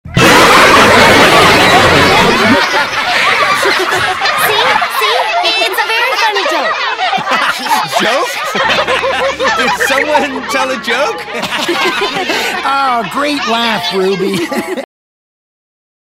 Giggles Laughing Ending Sound Button - Free Download & Play